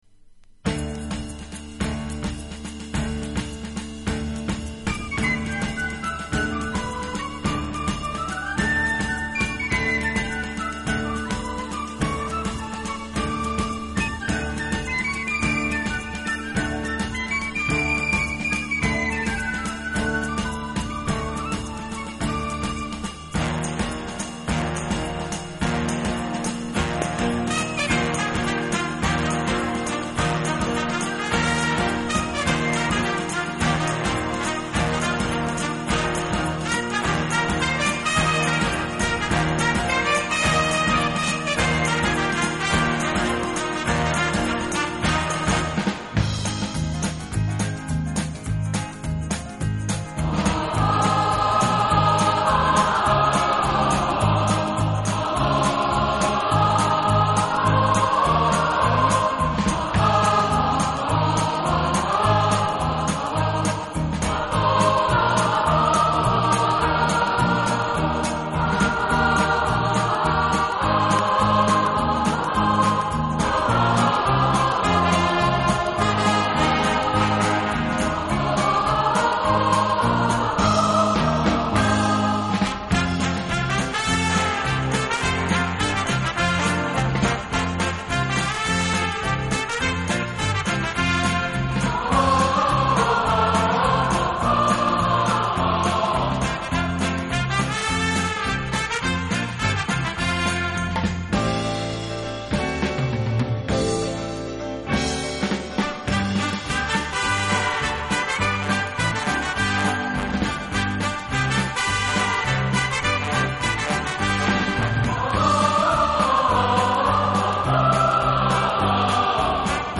【顶级轻音乐】
音乐类型pop, instrumental